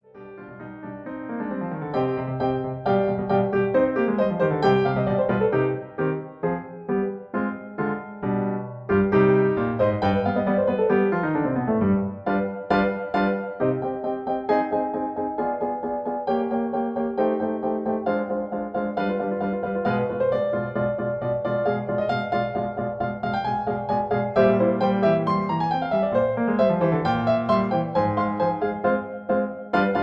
In C. Piano Accompaniment